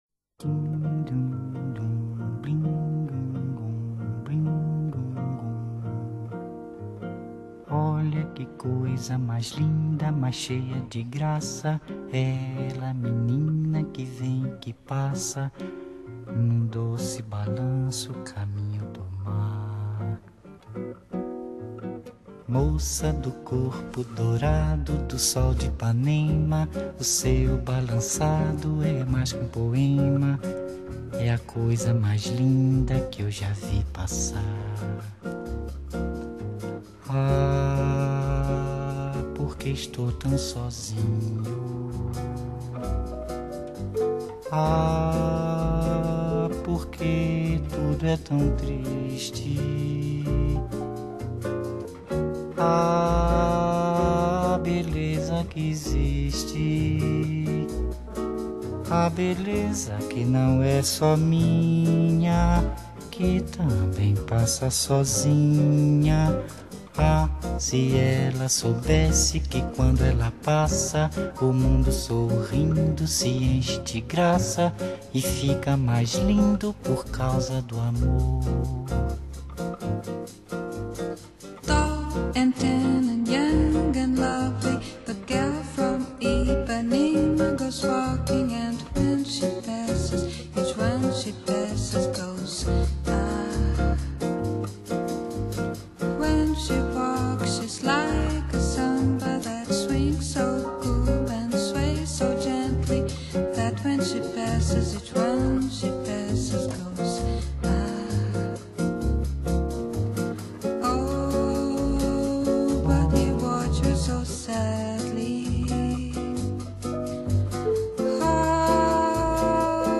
2000 Жанр: Jazz,Bossa Nova,Blues Формат
巴萨诺瓦的字面意思是「新节奏」，原来是一种拉丁音乐，听起来轻松柔和、慵懒甜美、浪漫性感。